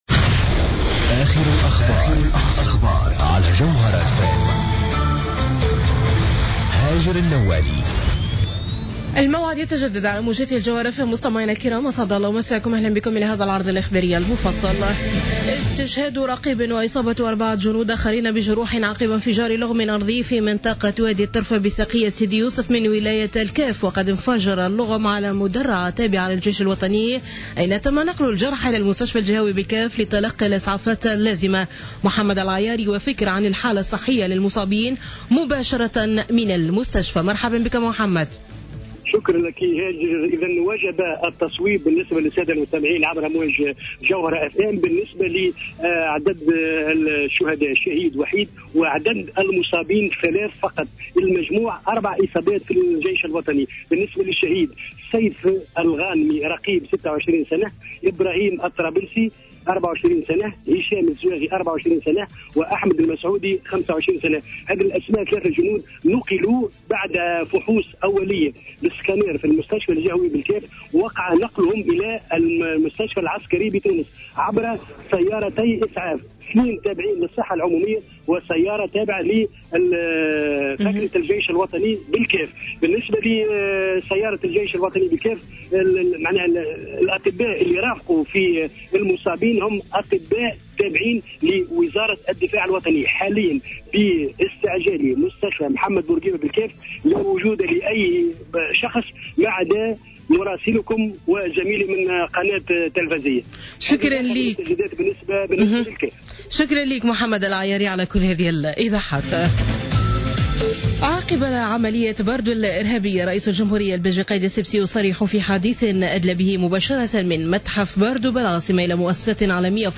نشرة أخبار منتصف الليل ليوم الاثنين 23 مارس 2015